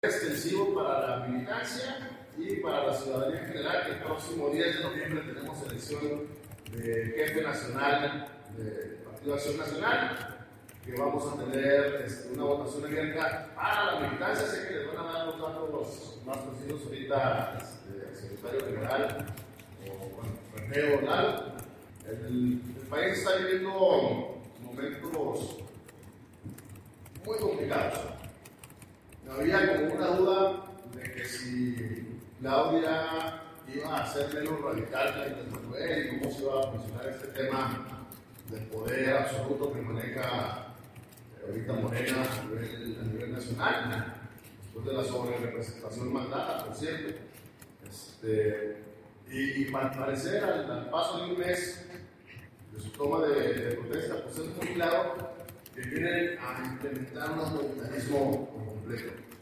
En una rueda de prensa del Comité Directivo Municipal del Partido Acción Nacional